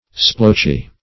Splotchy \Splotch"y\ (-[y^]), a.